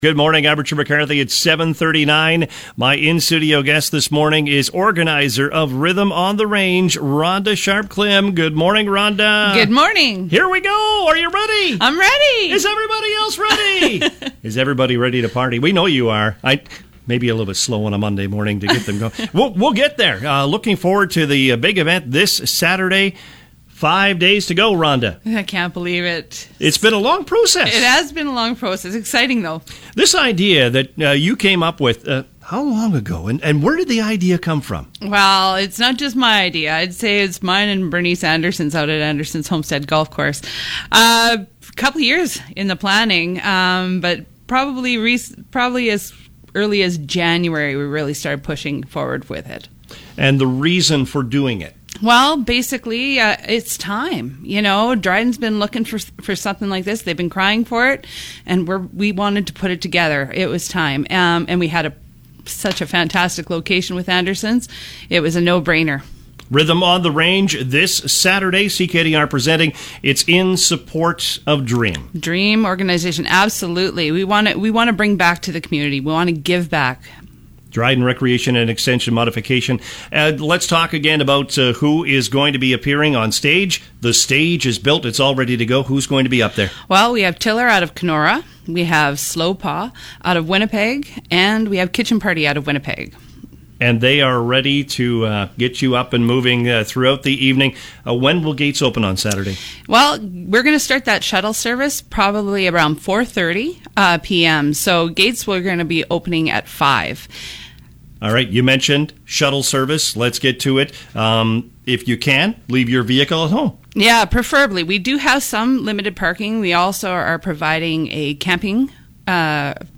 was a guest on the CKDR Morning Show today (Monday) to promote the big day.